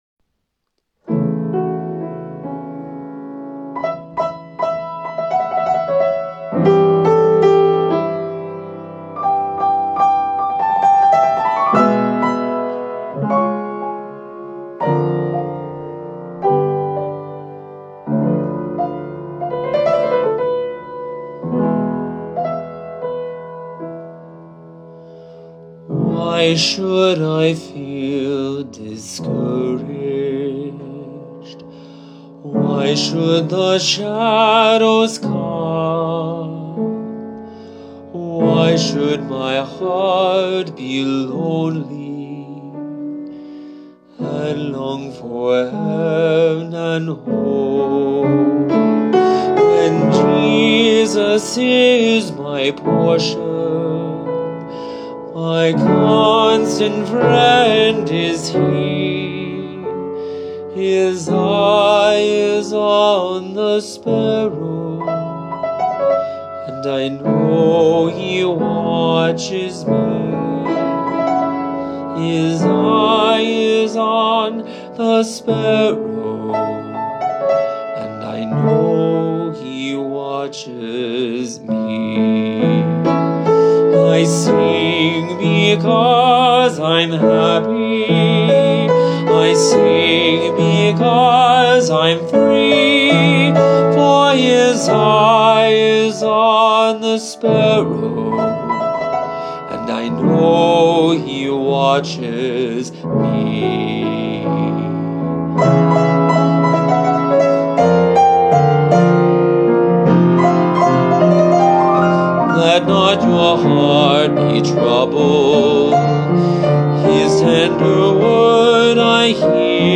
Voice, flute, and piano
Hymn arrangement.